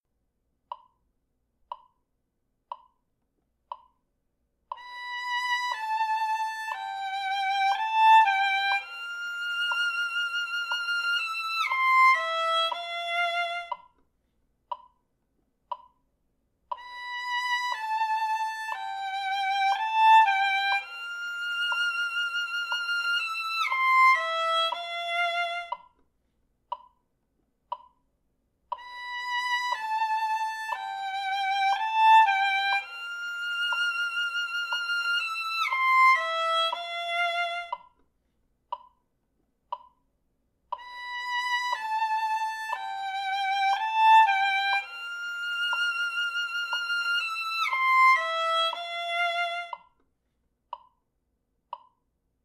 エルガー 弦楽セレナード 2楽章 ファーストヴァイオリンパート 音程の練習のために
彼女はちょっと音程で困っていたので、ご自宅での練習のために短い箇所のみ音源をつくりました。